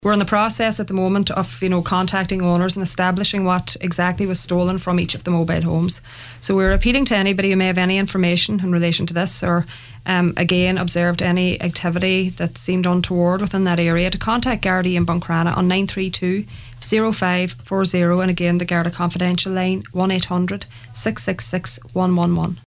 is appealing to anyone with information to come forward: